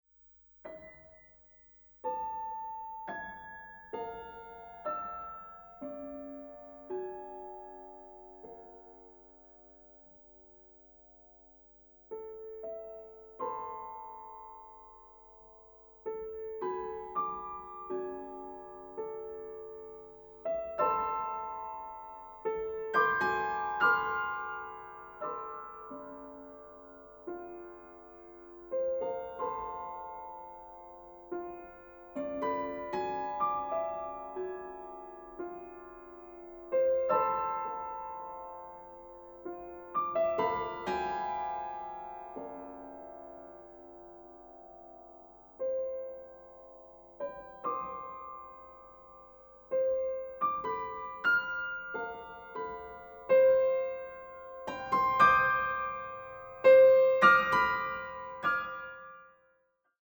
Klavierwerken aus sechs Jahrhunderten